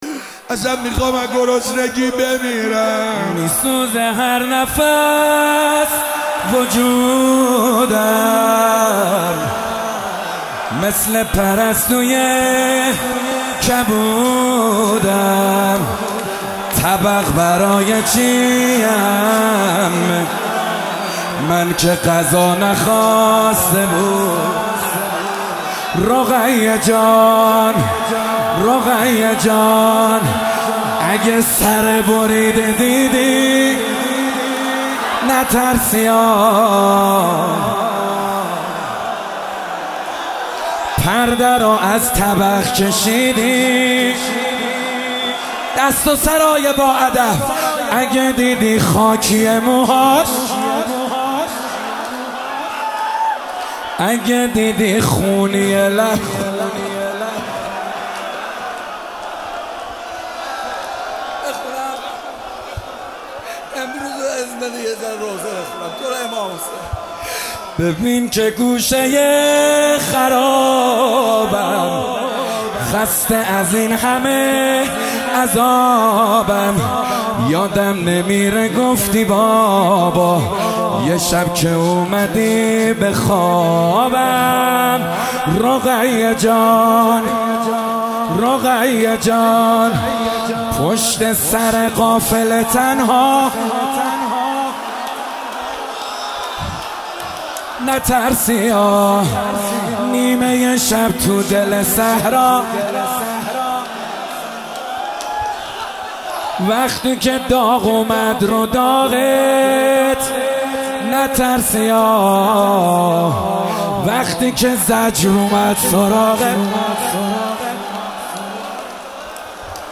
محرم98 - شب سوم - روضه
مهدیه امام حسن مجتبی(ع)